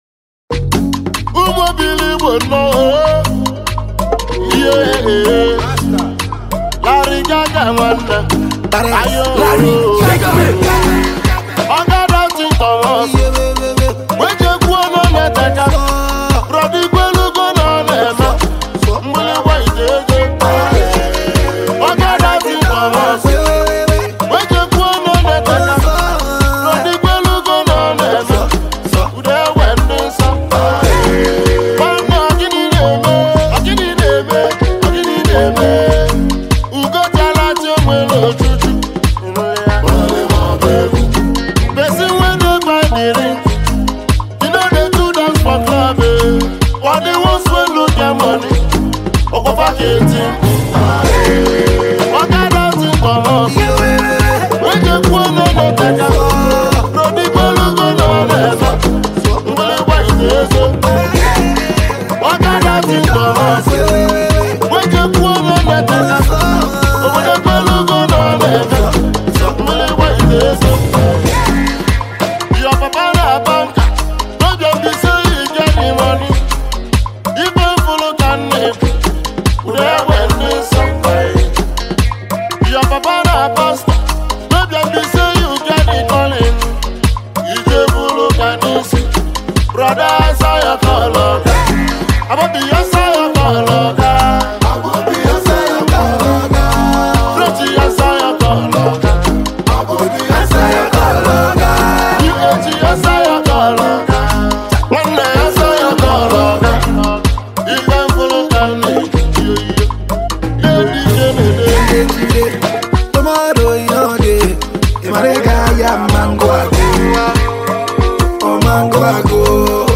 Eastern Hi-life singers
High Life duo